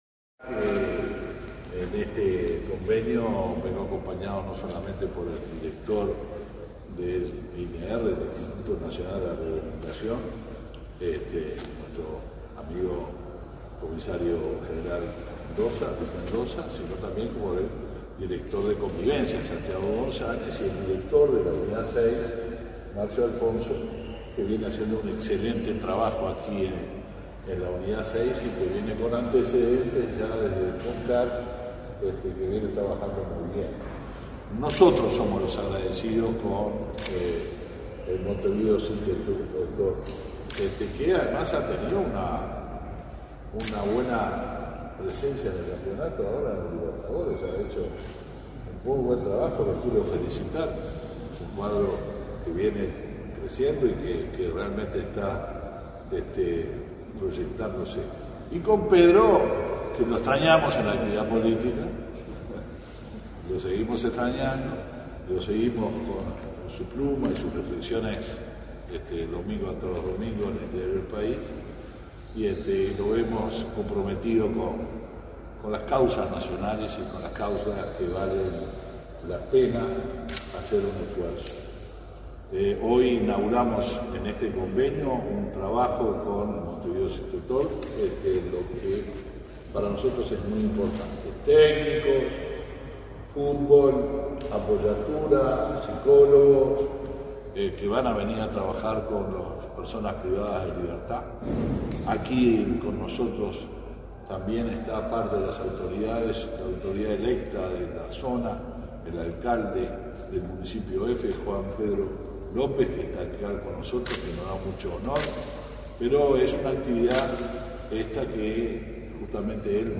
Conferencia de prensa por la firma de convenio entre el Ministerio del Interior y el club Montevideo City Torque